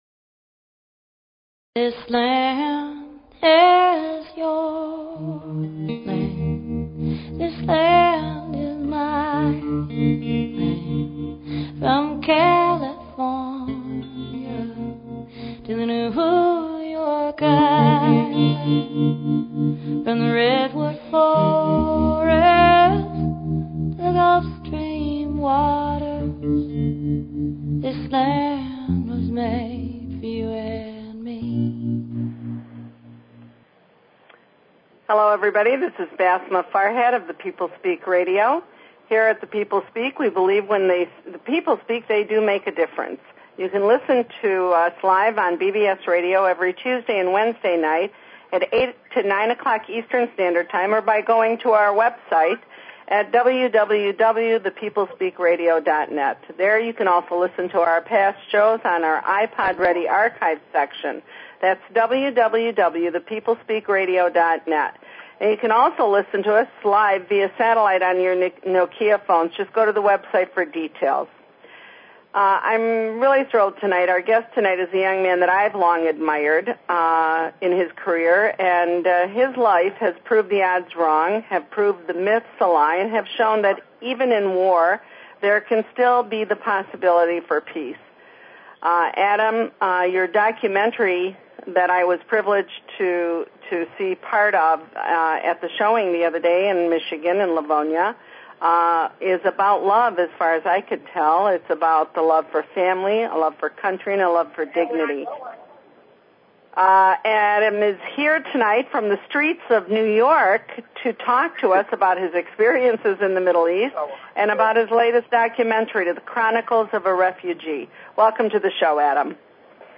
Talk Show Episode, Audio Podcast, The_People_Speak and Courtesy of BBS Radio on , show guests , about , categorized as
The show features a guest interview from any number of realms of interest (entertainment, science, philosophy, healing, spirituality, activism, politics, literature, etc.).